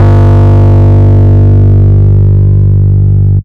longest Bass Live.wav